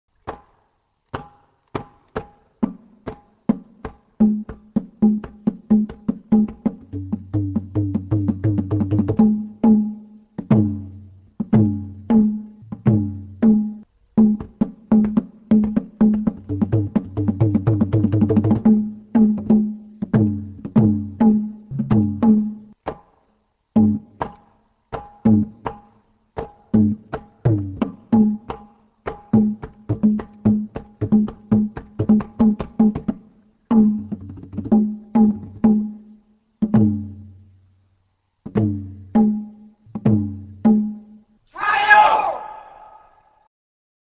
As for the Thai drum, it is put on a stand and played on both heads with the palms of the hand and the fingers. The ta phon is one of the rhythm instruments used in the pi phat ensemble.